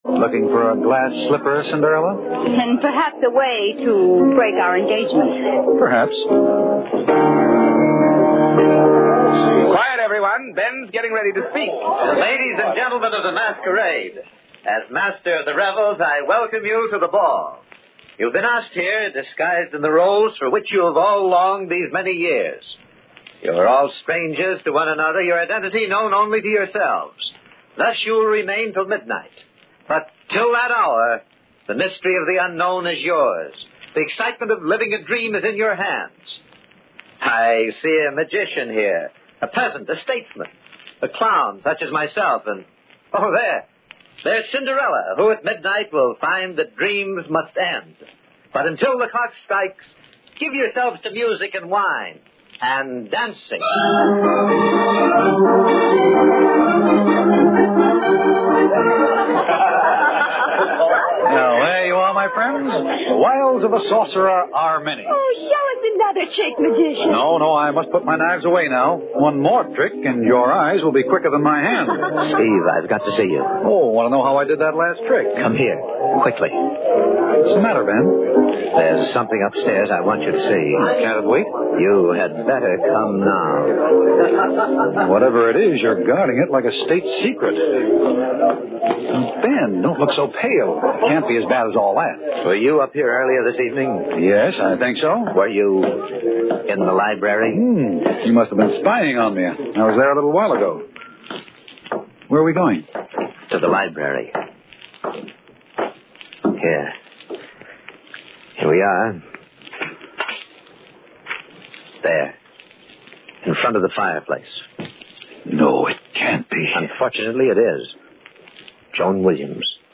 Tags: Radio Horror Mystery Radio Show The Haunting Hour